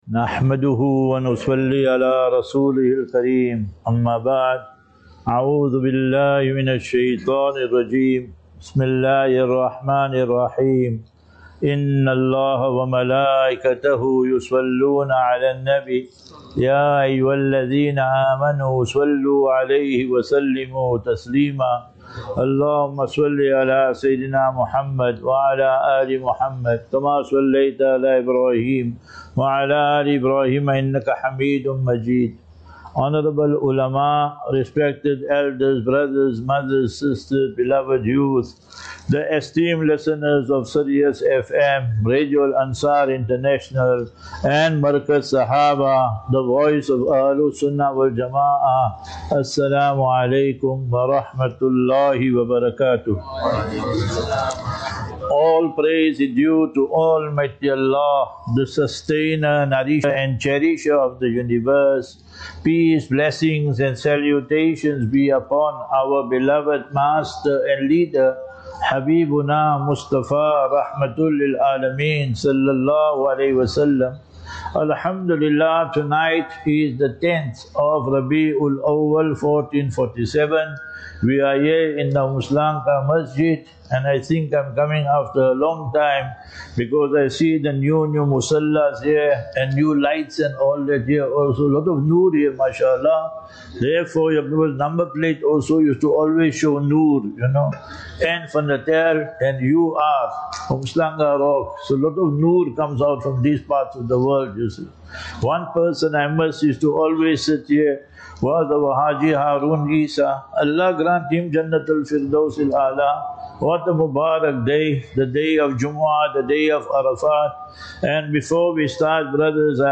Lectures
Umhlanga Masjid